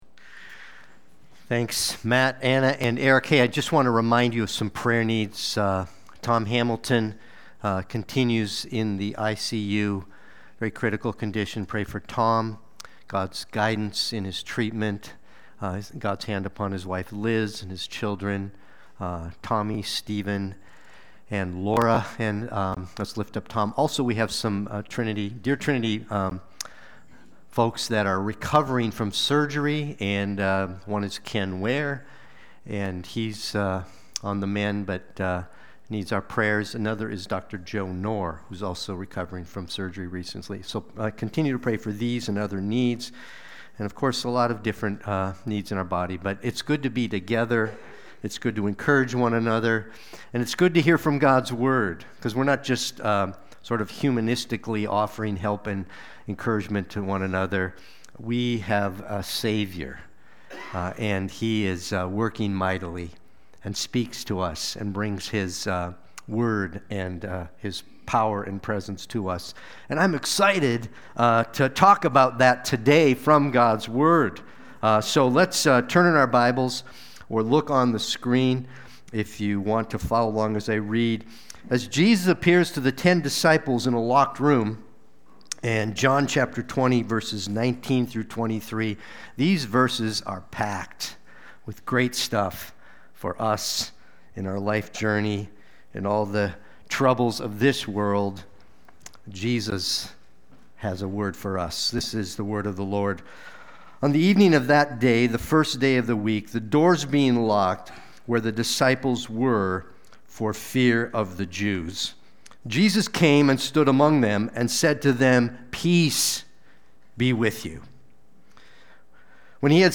Watch the replay or listen to the sermon.
Sunday-Worship-main-32325.mp3